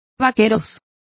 Also find out how vaqueros is pronounced correctly.